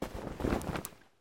Звук резкого стаскивания футболки с тела